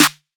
SOUTHSIDE_snare_mpc_style.wav